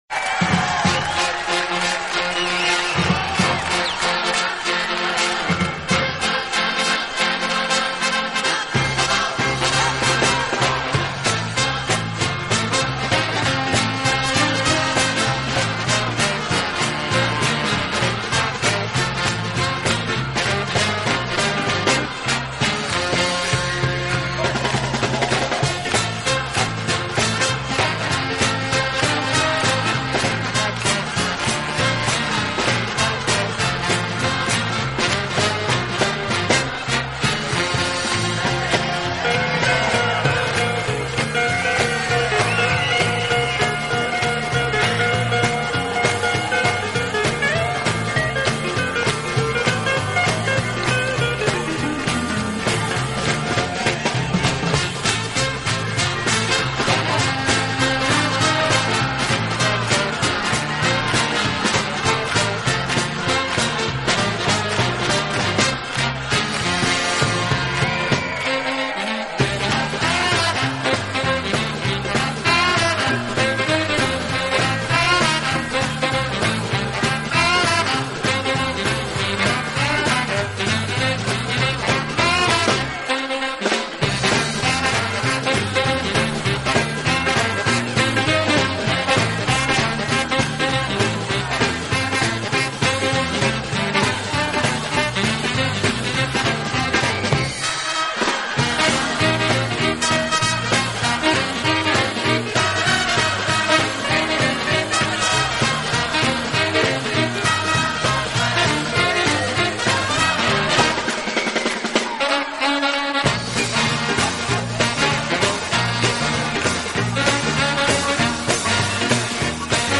【轻音乐】
【顶级轻音乐】